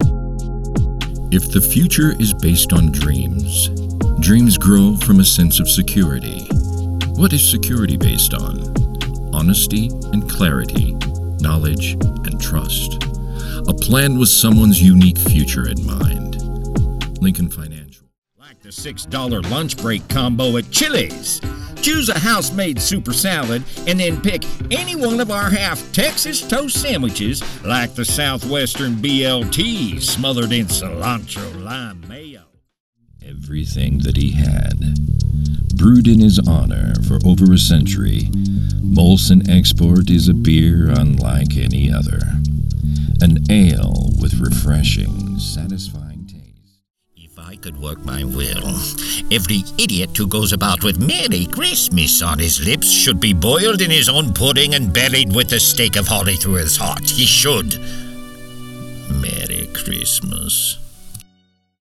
Fanta campaigns featured on The Voice Realm showcase playful and dynamic voices.